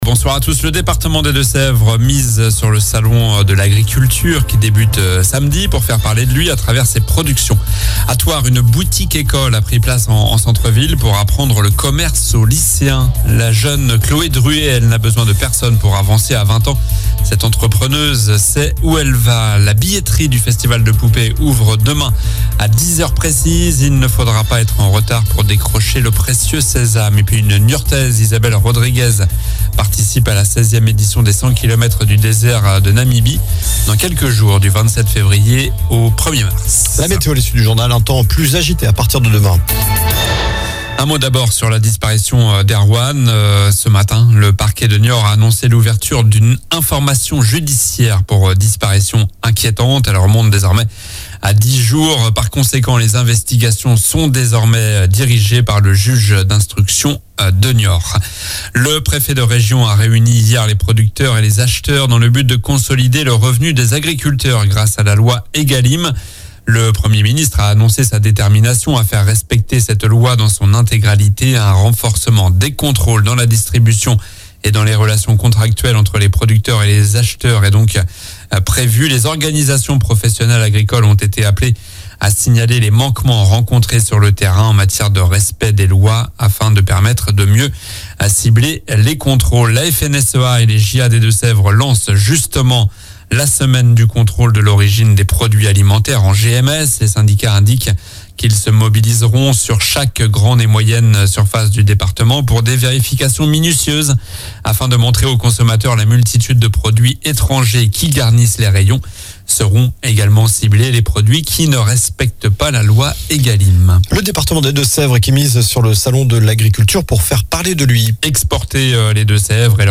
Journal du mardi 20 février (soir)